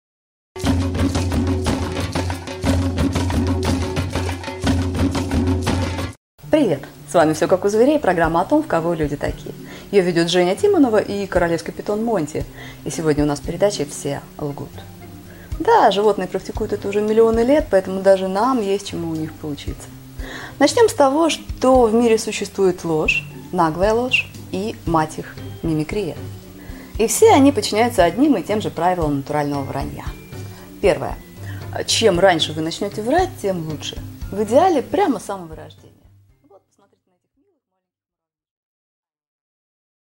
Аудиокнига Ложь, наглая ложь и мать их мимикрия | Библиотека аудиокниг